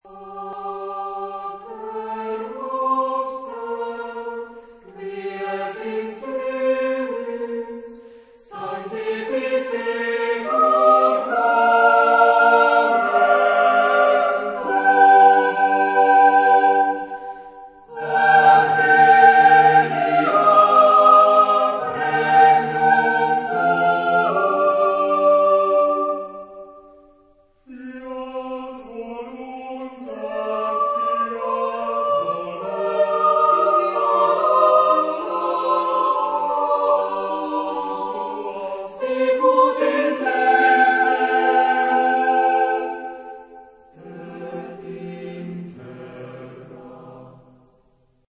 Genre-Style-Forme : Sacré ; Prière
Caractère de la pièce : avec dignité ; révérencieux ; modéré
Type de choeur : SATB  (4 voix mixtes )
Tonalité : do majeur
Consultable sous : 20ème Sacré Acappella